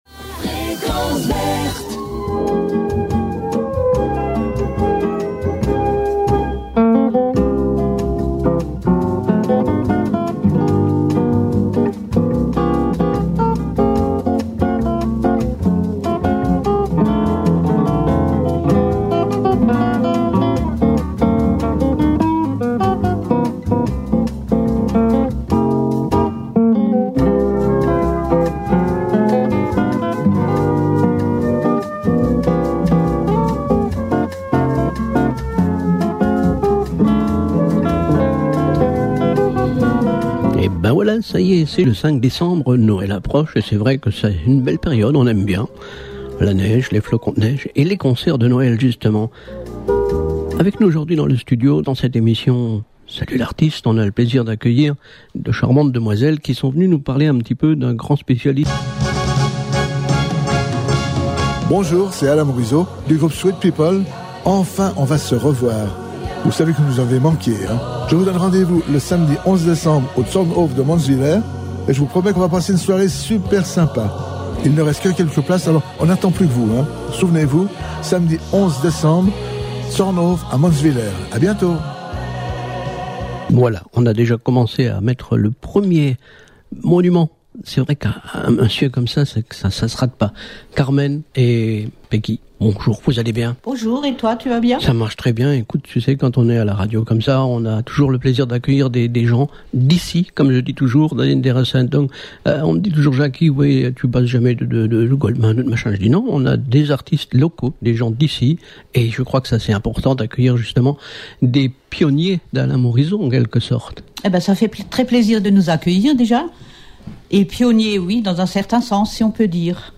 Radio Frequence Verte Interview 5 décembre 2021
Retrouvez notre interview, le 5 Décembre 2021 de 9h à 10h sur Radio Frequence Verte !!!